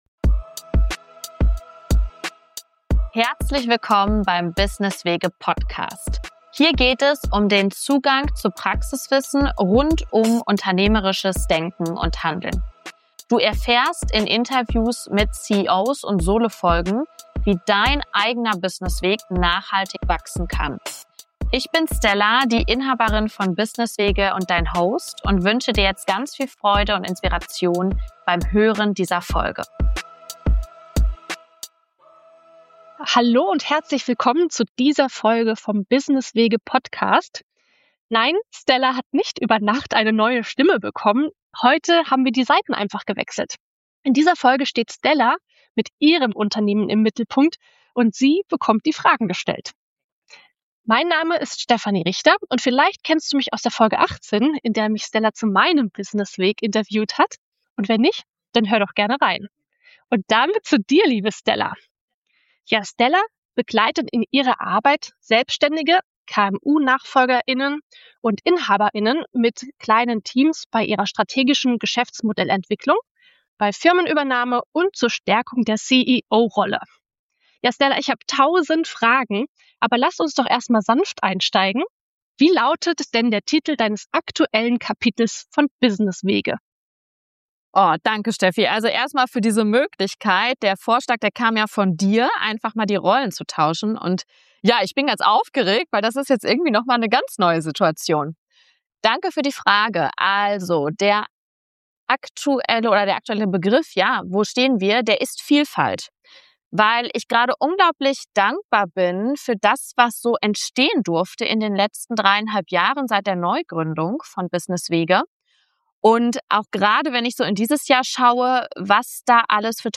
#30 CEO-Talk